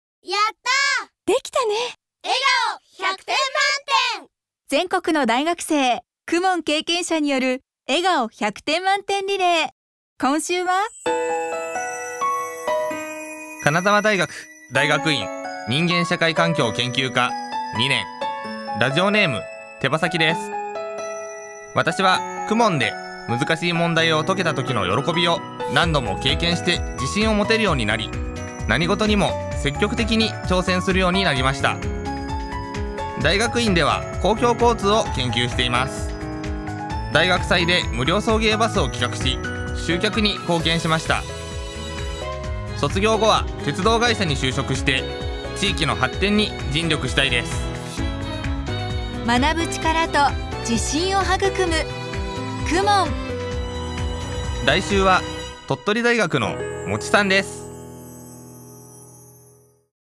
全国の大学生の声